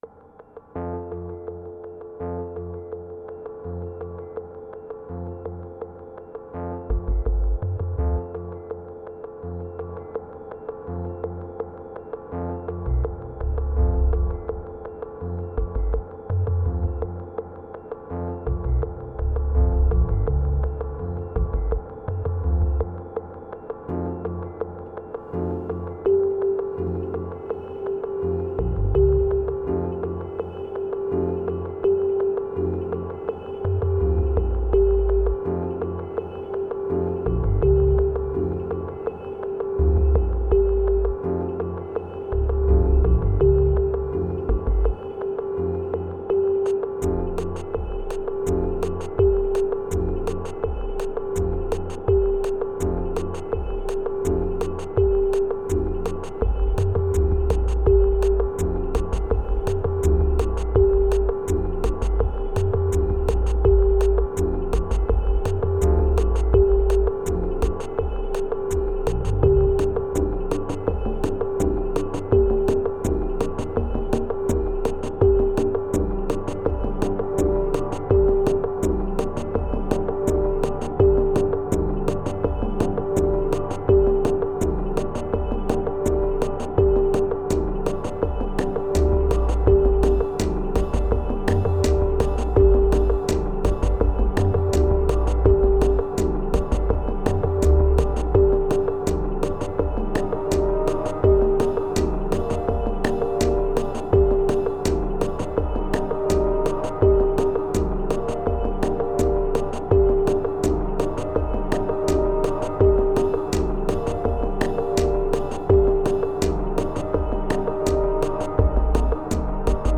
Prepare your subwoofer or your deep headz.